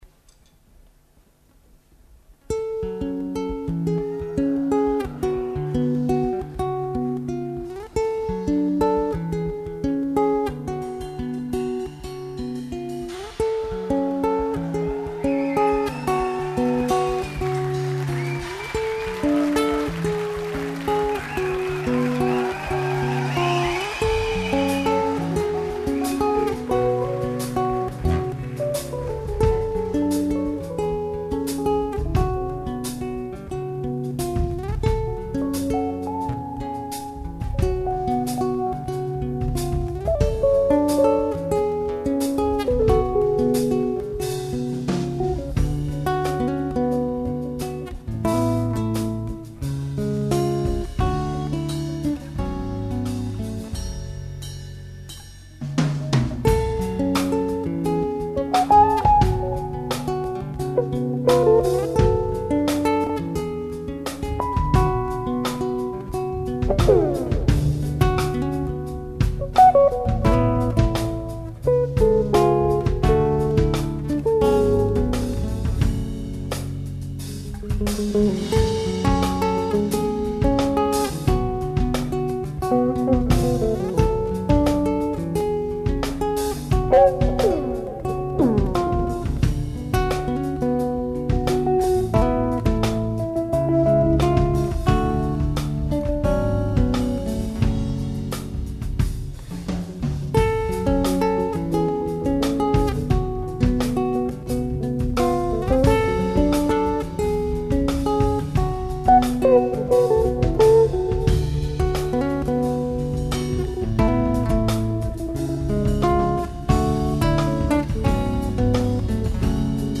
[live 2003]
라이브 협연입니다